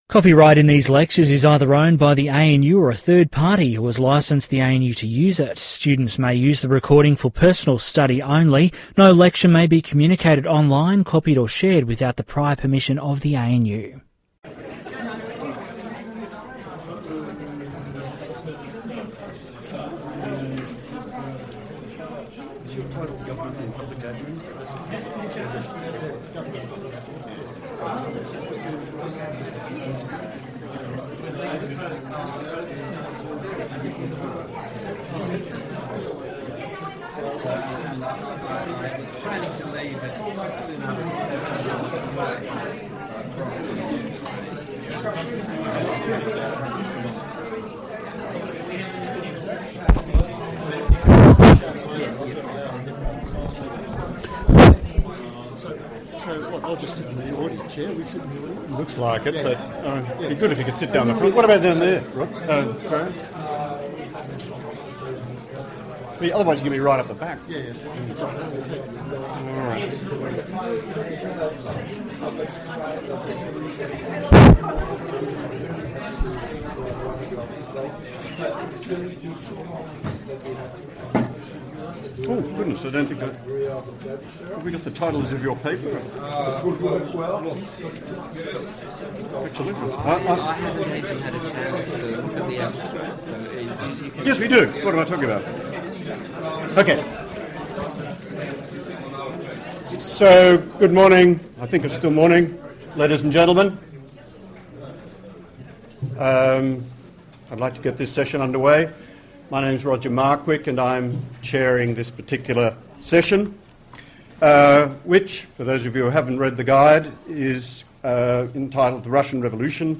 The recent AACaPS Conference at The Australian National University featured a plenary panel entitled ‘The Russian Revolution: A Century of Communist and Post-Communist Development’.